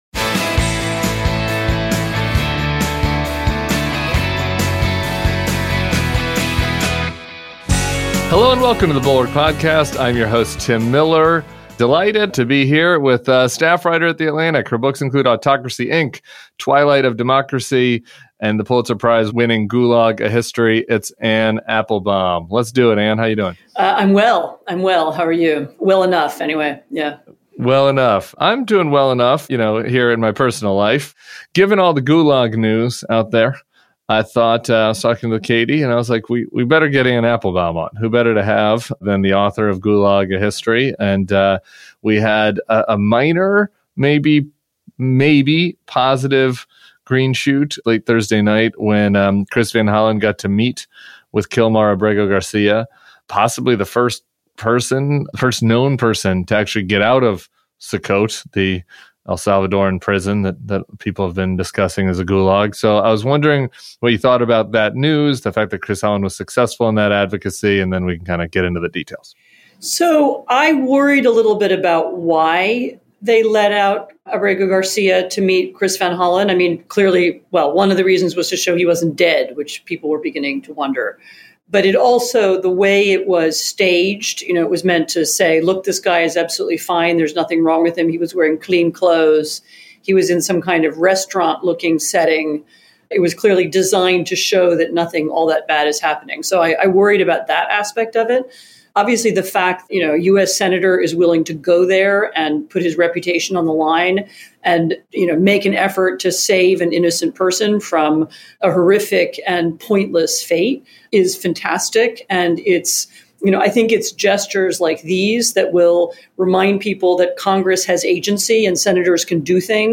Anne Applebaum joins Tim Miller for the weekend pod. show notes Anne's recent piece, "Kleptocracy, Inc."